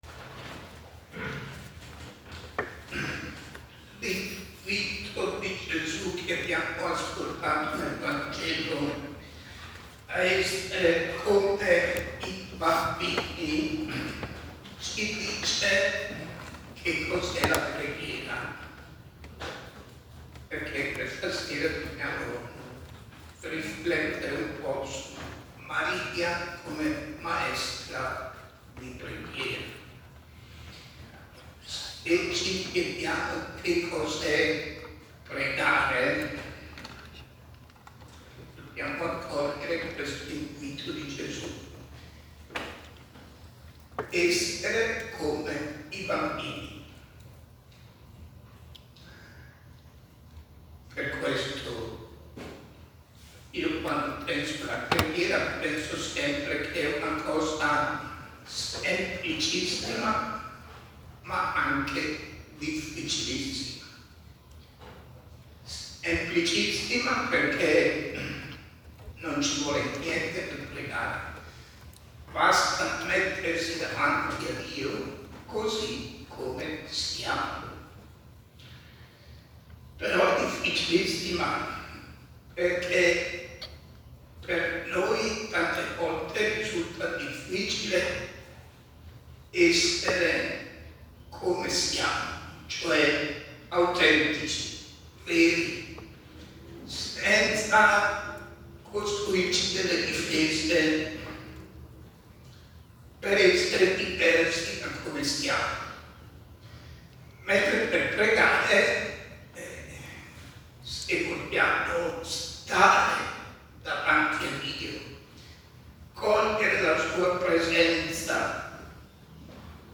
riflessione: Maria, maestra di preghiera